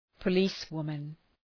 Προφορά
{pə’li:s,wʋmən}